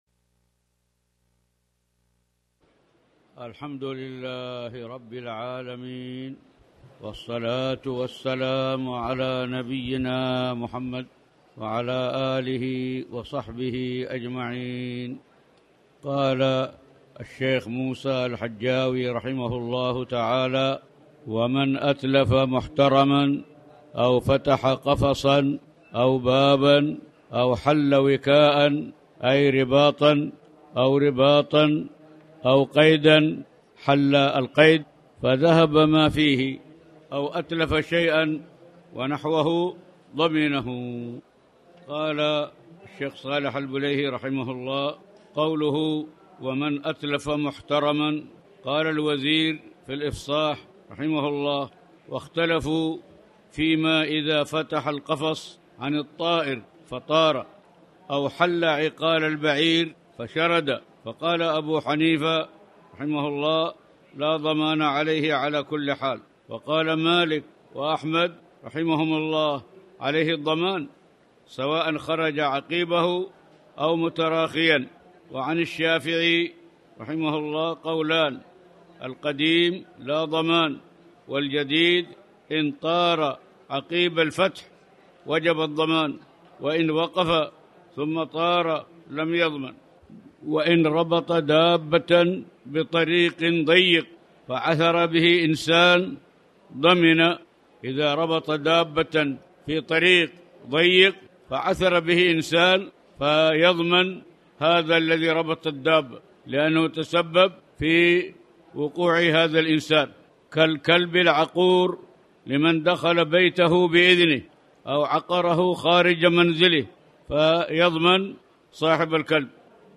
تاريخ النشر ١٥ ربيع الثاني ١٤٣٩ هـ المكان: المسجد الحرام الشيخ